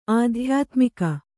♪ ādhyātmika